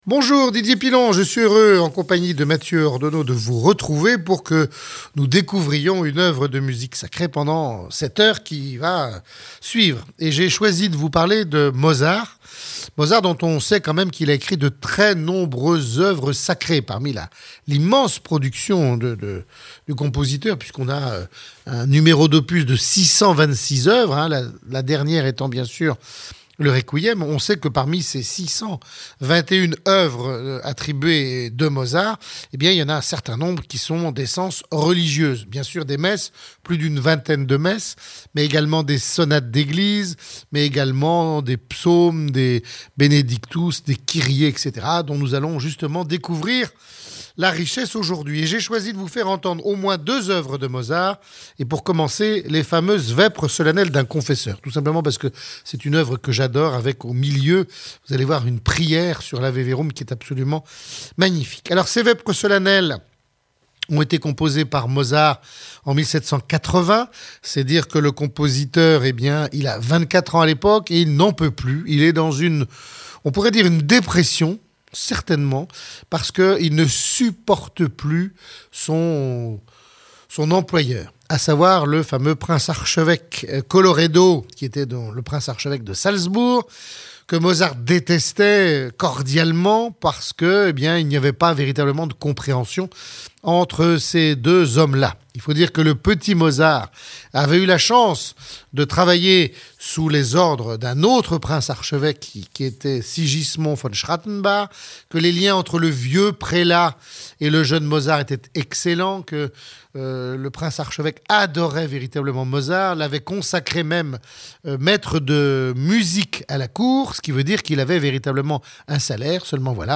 DP-Mozart - Oeuvres sacrees